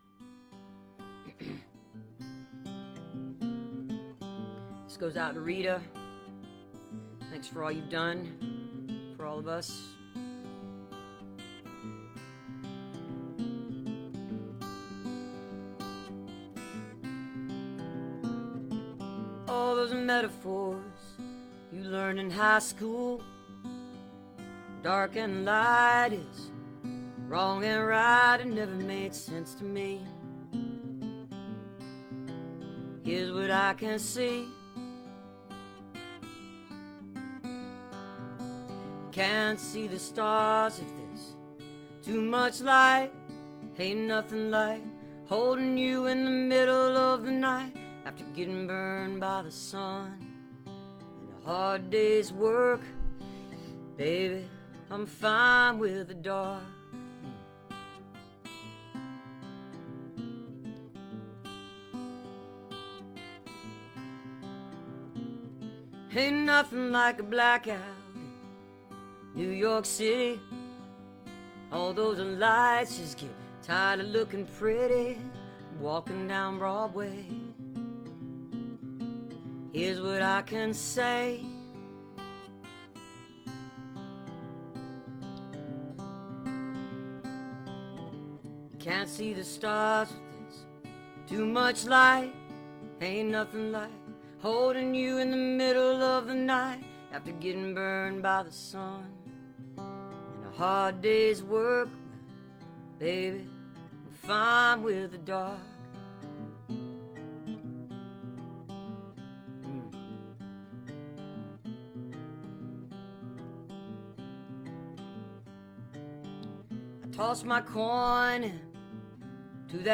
(captured from facebook)